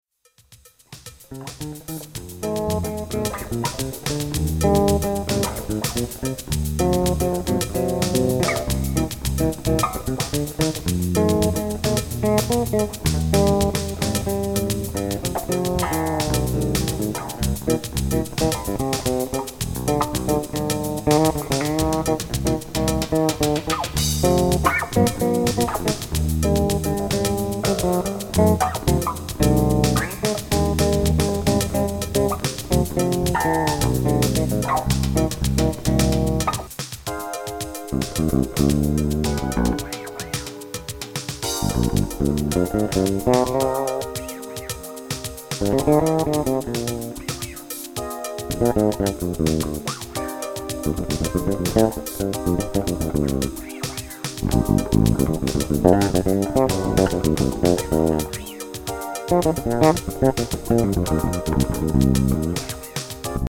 Style JAZZ